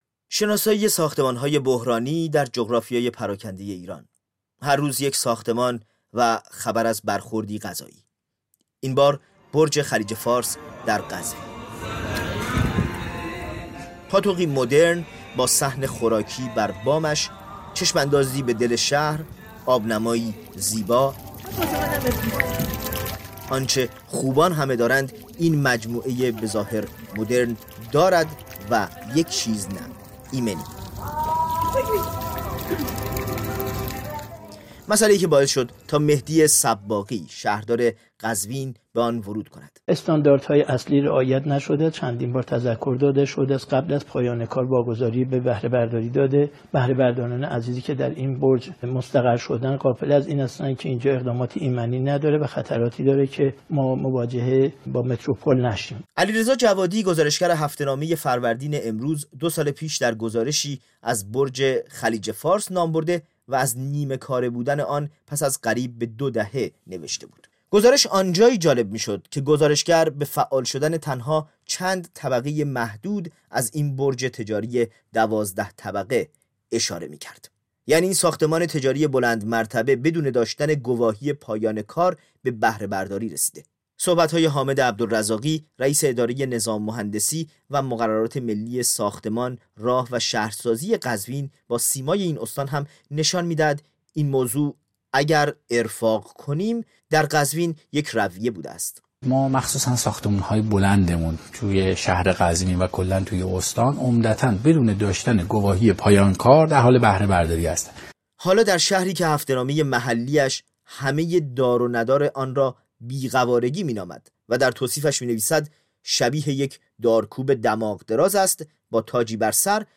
گزارش می دهد.